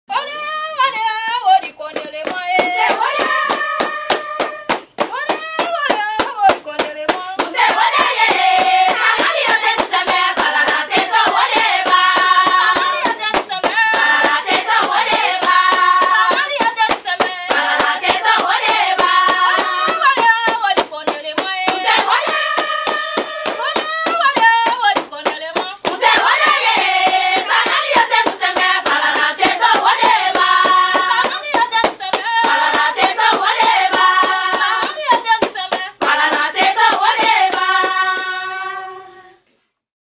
Na het diner geeft een groepje van tien vrouwen uit het dorp een demonstratie van hun traditionele zang- en danskunst. Het zijn liederen die nog stammen uit de tijd van de slavenarbeid  op de plantages.
Dan Paati - Seketie dans